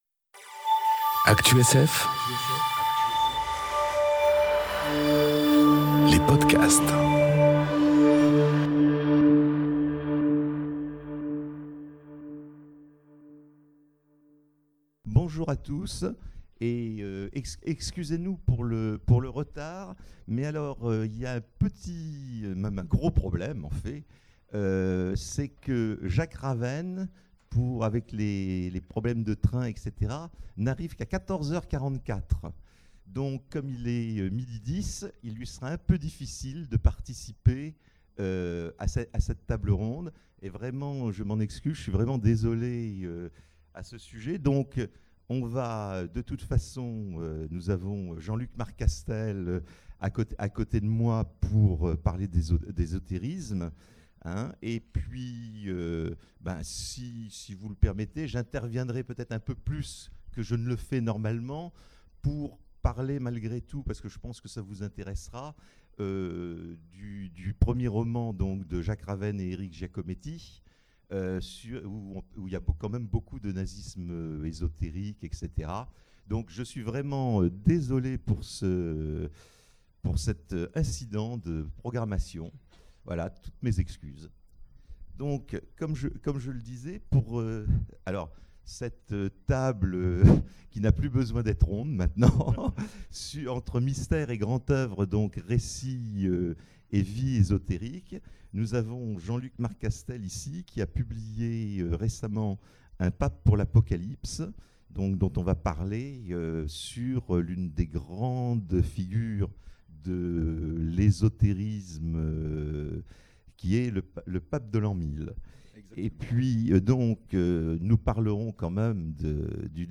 Imaginales 2018 : Conférence Entre mystères et grand oeuvre : récits et vies ésotériques...